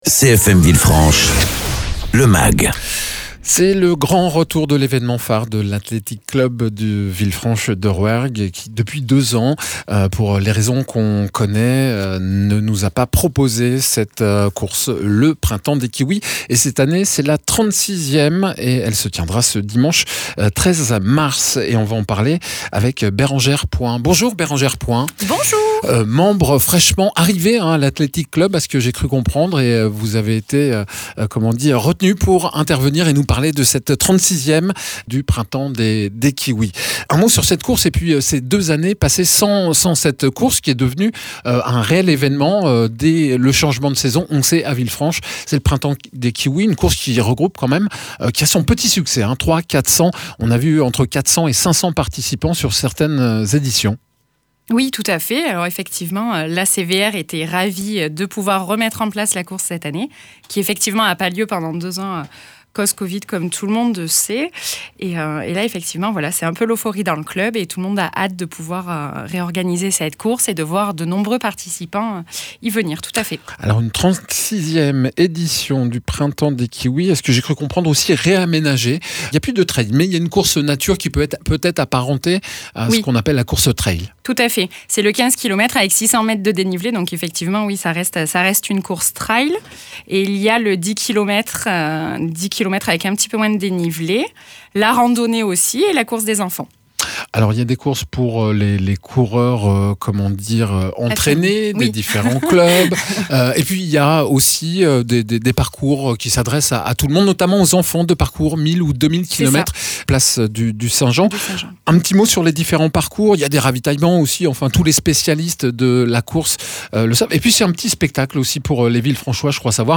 Au programme quatre parcours, une course nature, une sur route, deux pour les enfants et une randonnée. Une interview, l’occasion de parler des activités du club.
Interviews